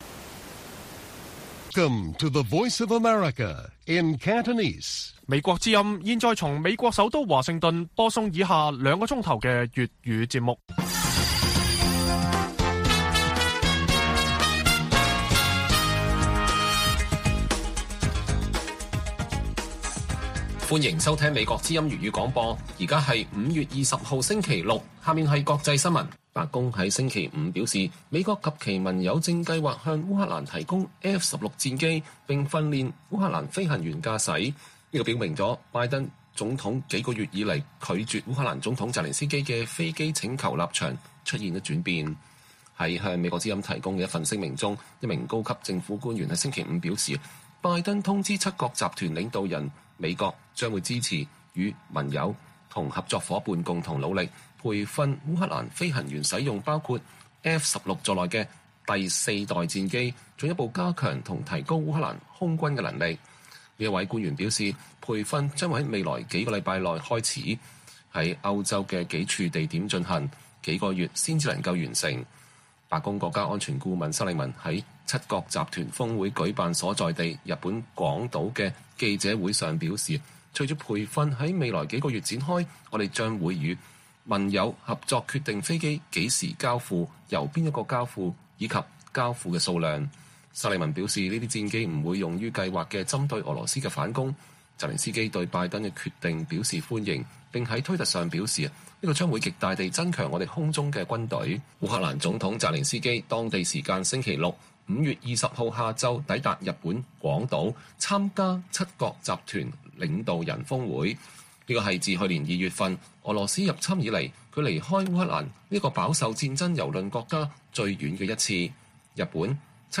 粵語新聞 晚上9-10點 : 挑戰國安委 要求釋法不影響聘海外律師 黎智英申請司法複核被拒